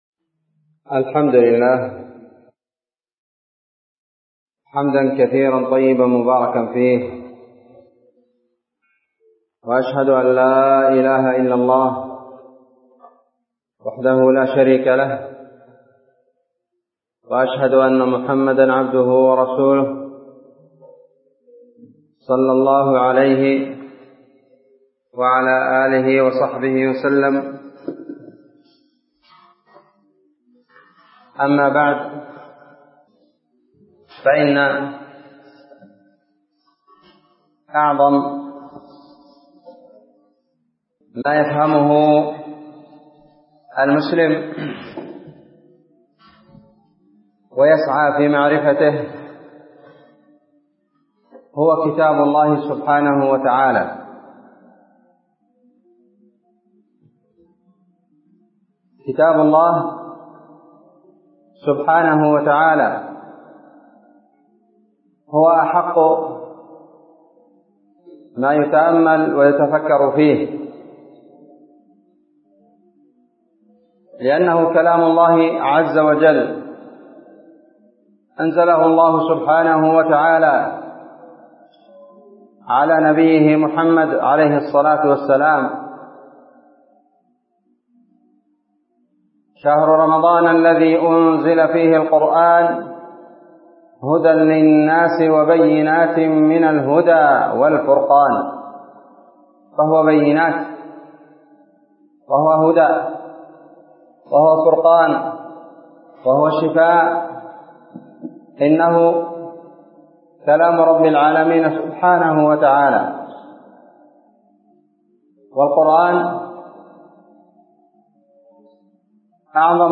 كلمة